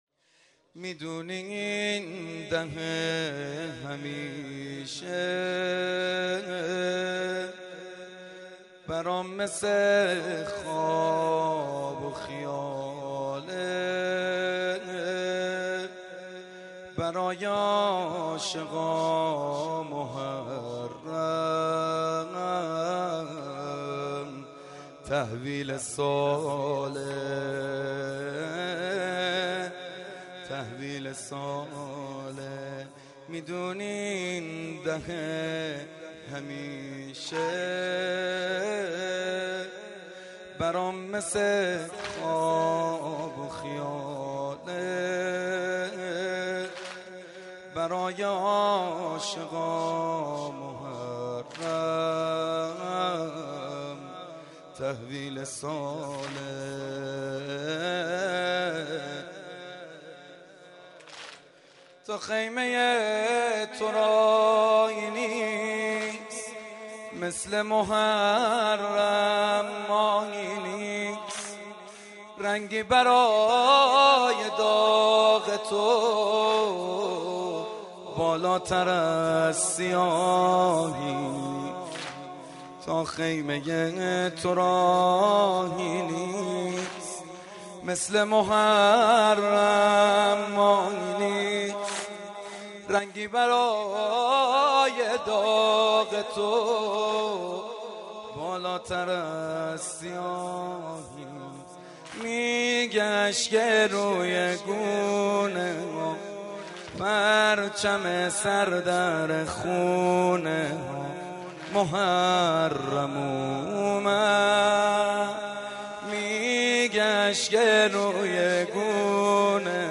صبح تاسوعا، نهم محرم 93 / حاج سعید حدادیان